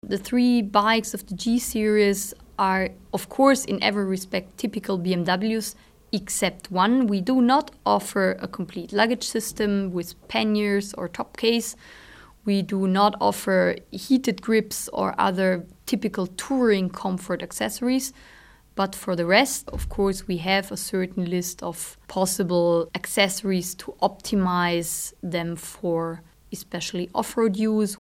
O-Ton
Monza, Italien, 09.05.09. Liveübertragung der Weltpremiere der neuen BMW S 1000 RR.